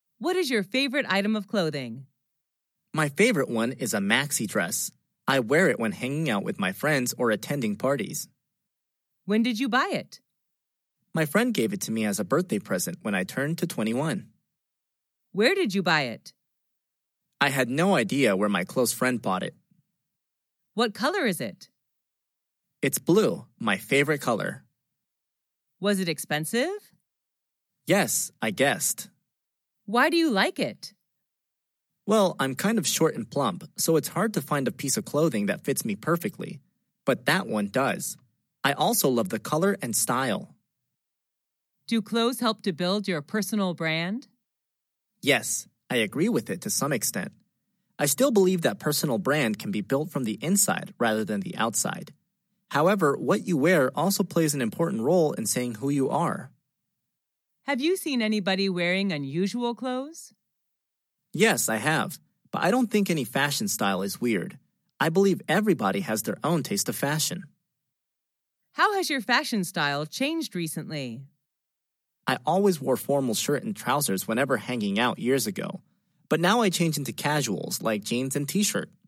Sách nói | QA-48